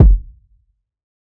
TC2 Kicks16.wav